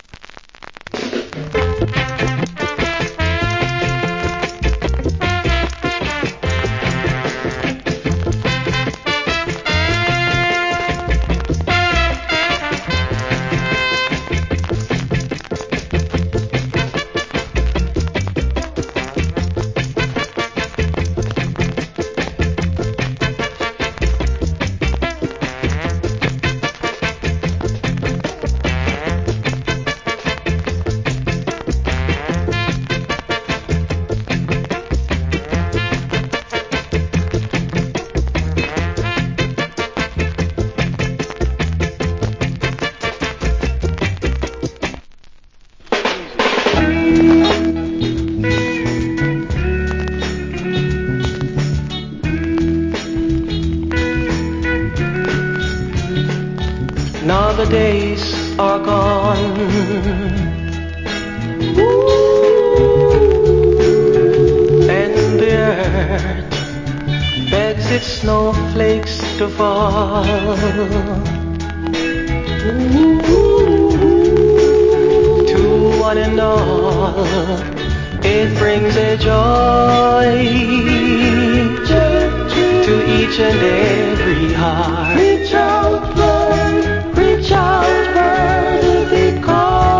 Wicked Early Reggae Inst.